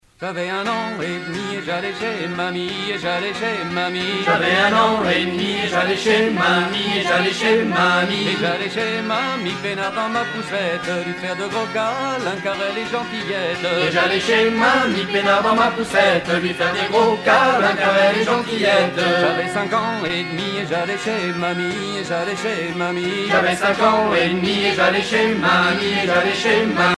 danse : rond de Saint-Vincent
Pièce musicale éditée